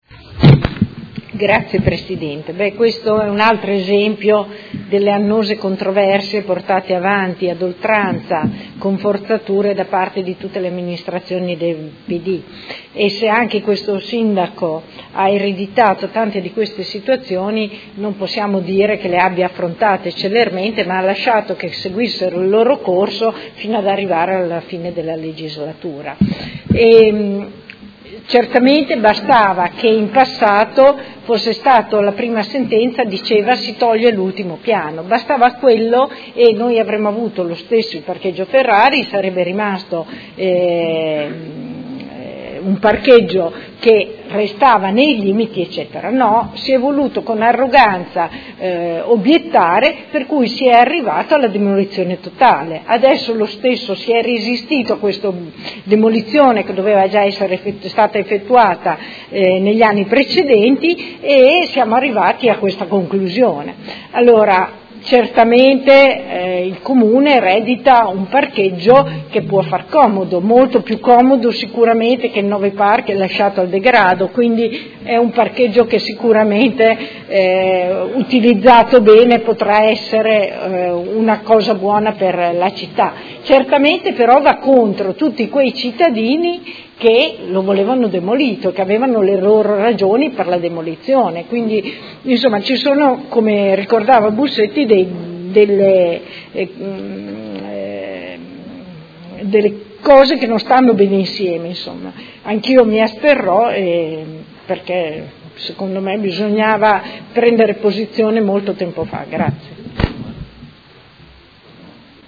Luigia Santoro — Sito Audio Consiglio Comunale
Seduta del 20/12/2018. Dibattito su proposta di deliberazione: Dichiarazione di prevalente interesse pubblico ai fini del mantenimento dell'immobile sito in Modena, viale Trento Trieste, catastalmente identificato presso il Catasto Urbano di Modena al foglio 144, mappale 141 subalterni 10,13,18, 19, 21, 23, 25, 36, 27, 28, 29 comprese aree di sedime e aree pertinenziali, ex Ditta B.A. Service S.r.l. ai sensi del comma 5 art. 13 Legge Regionale 23/2004, e mozione